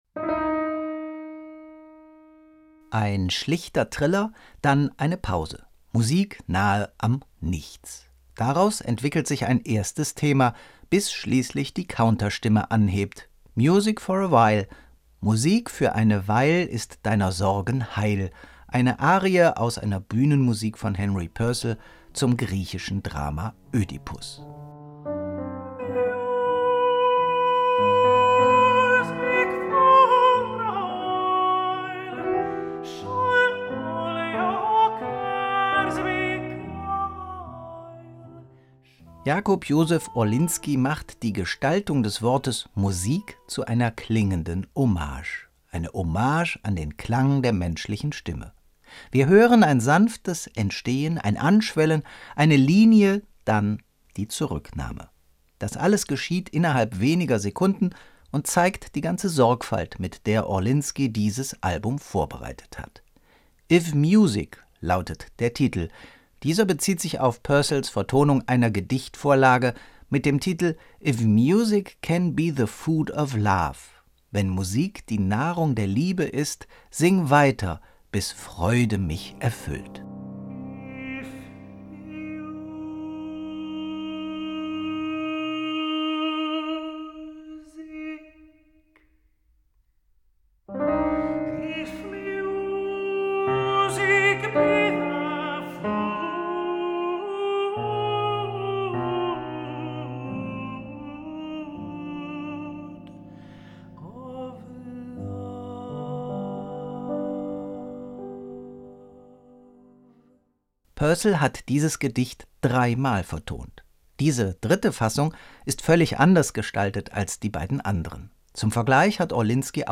Jakub Józef Orliński präsentiert mit „if music…“ ein intimes Album voller Barockmusik. Begleitet von Klavier statt Orchester, setzt er auf musikalische Essenz.
Betörend ist immer wieder die Natürlichkeit des Gesangs.
Da passt es gut, dass die Stimme des Countertenors so ausgeglichen funktioniert, ob in der Höhe, der Tiefe oder in der Mitte.